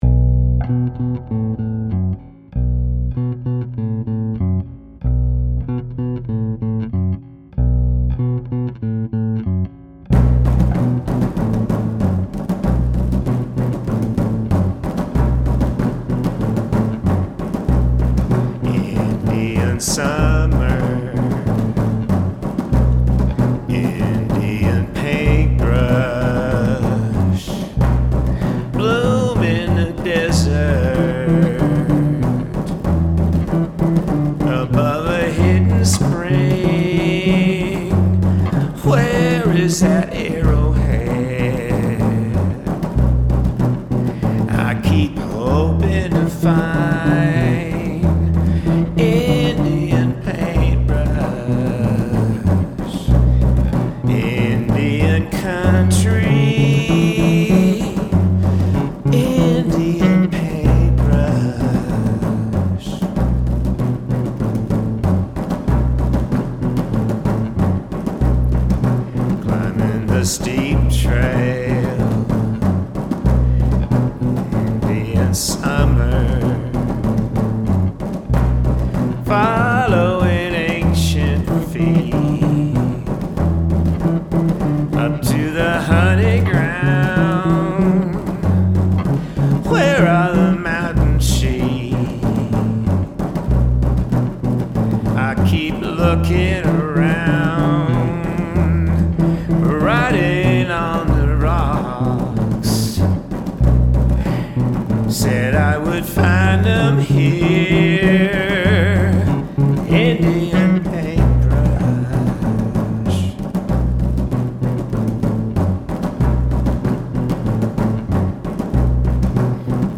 But I wanted to share these lo-fi recordings of works in progress with our community, because who knows if I’ll ever have time to polish them for mass consumption?
I started composing most of them with the electric bass